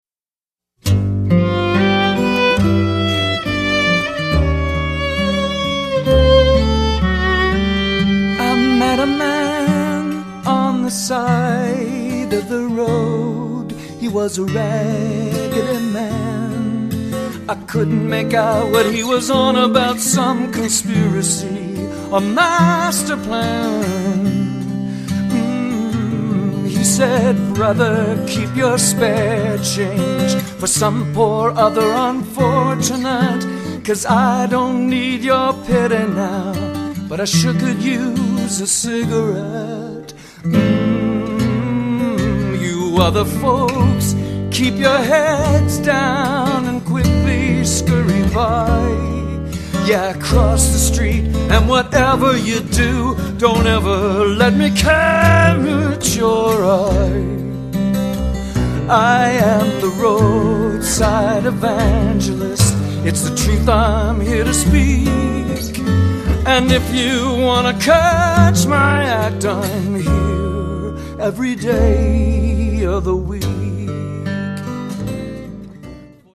lead vocals, guitars, national steel, harmony vocals
violin
upright bass
percussion